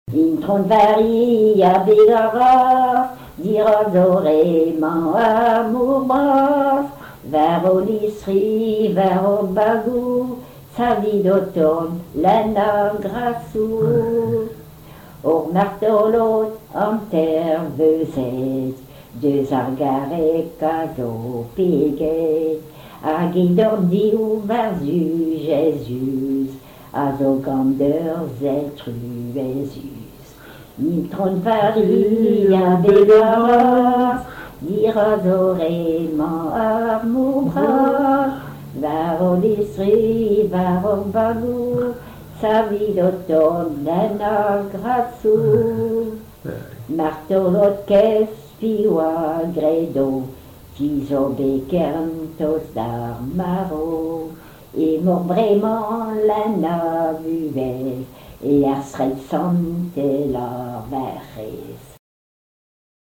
circonstance : dévotion, religion
Genre strophique
Pièce musicale inédite